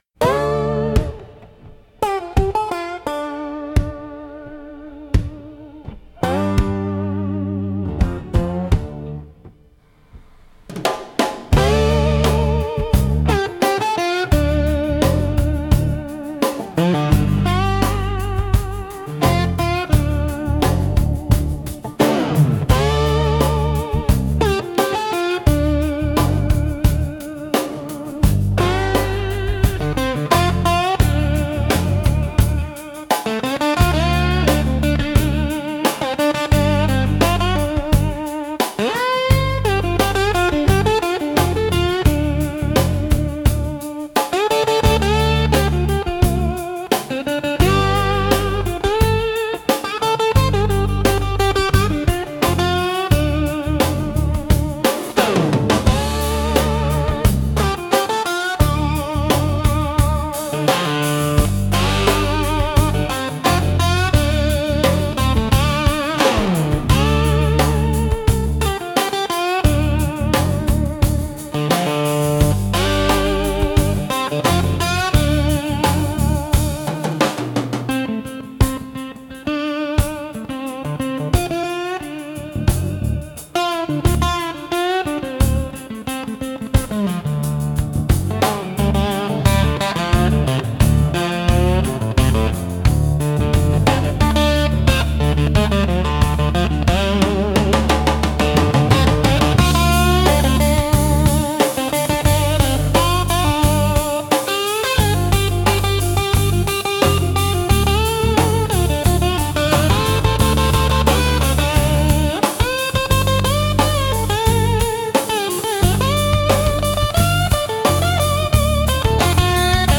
Instrumental - Swampfire Shuffle